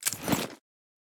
armor-close-1.ogg